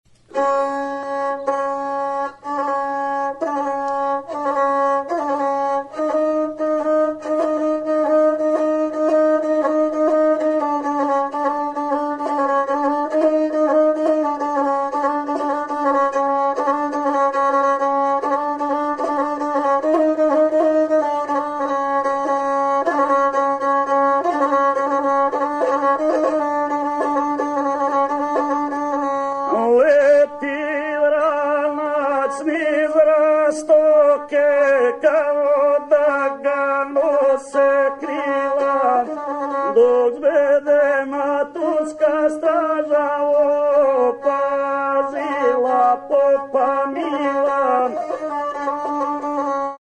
Stringed -> Bowed
(LIVE)
GUSLE